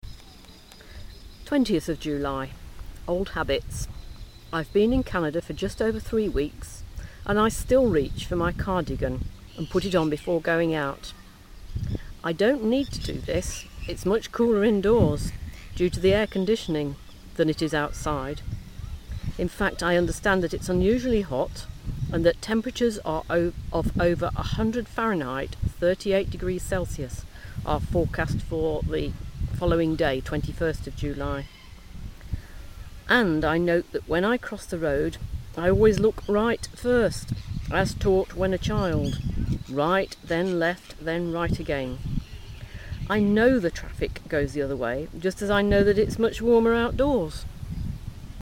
Old Habits (outside broadcast)